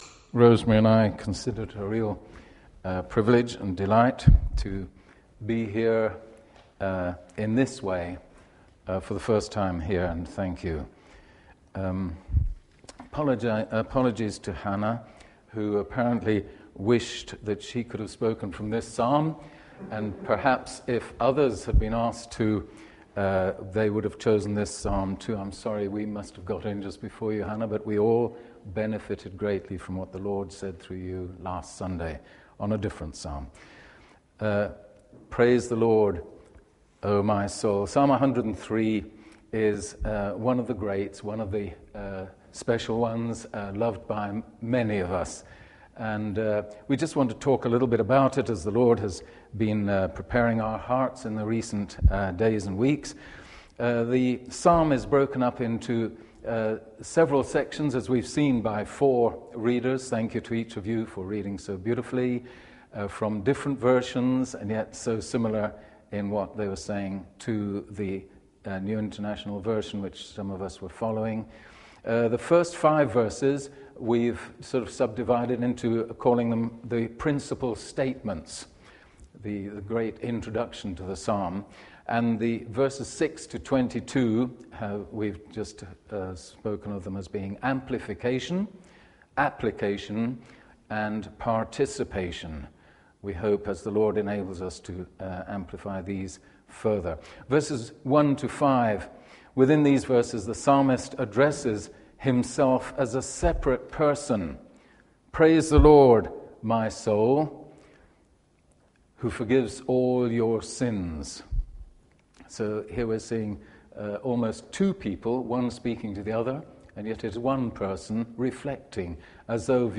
Talks
Ruddington Baptist Church